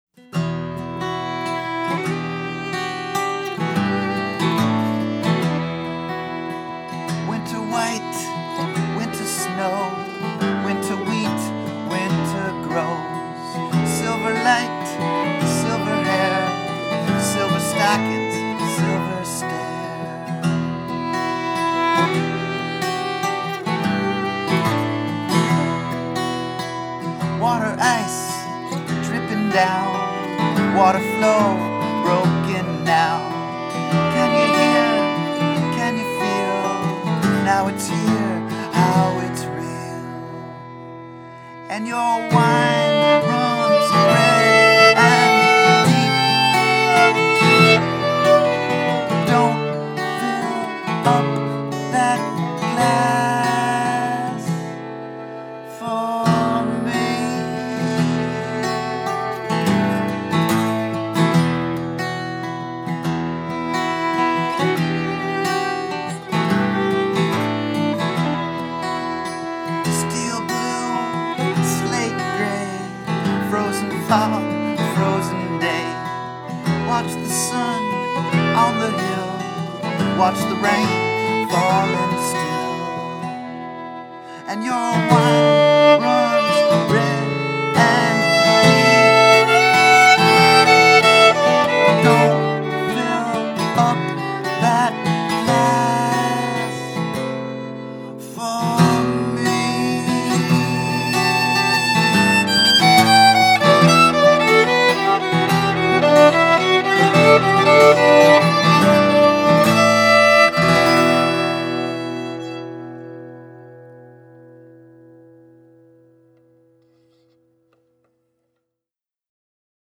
Guitar and vocals
Mandolin, fiddle, and vocals